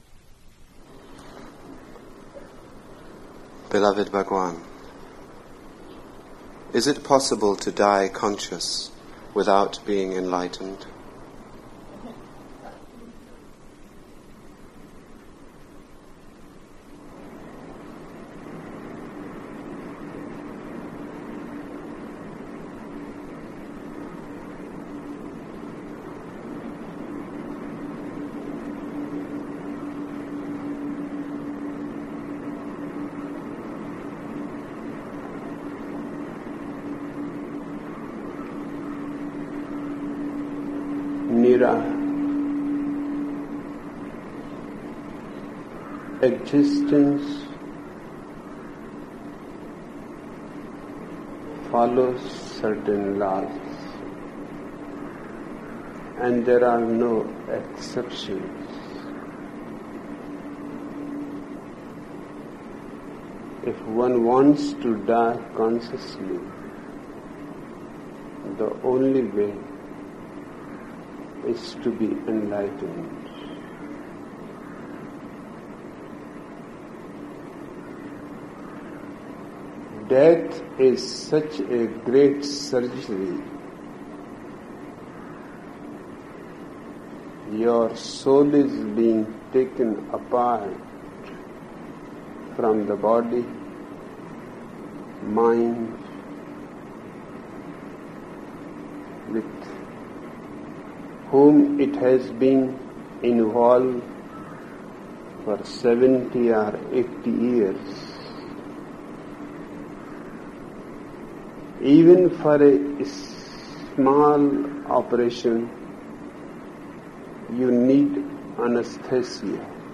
Listening Meditation: